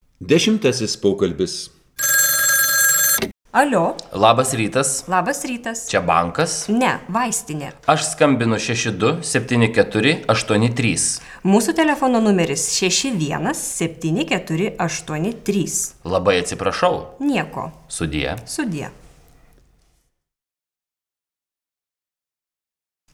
02_Dialog_10.wav